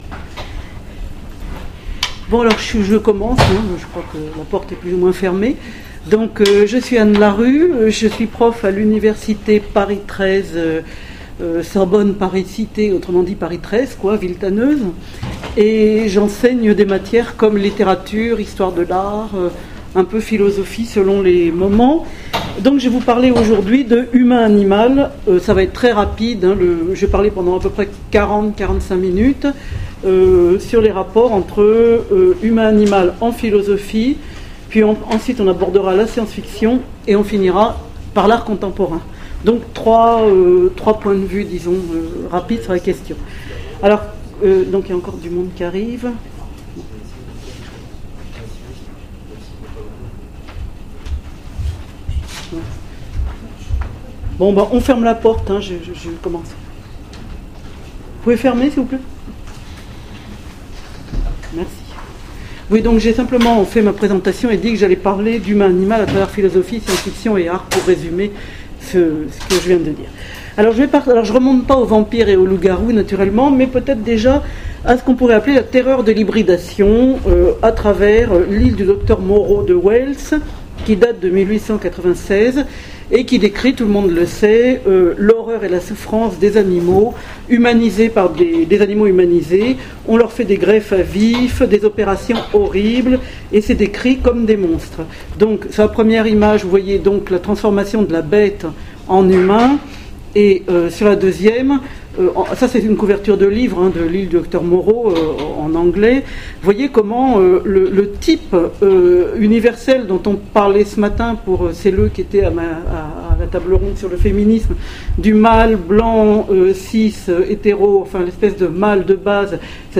Utopiales 2014 : Cours du soir - Humain/Animal : art, science-fiction, philosophie
Conférence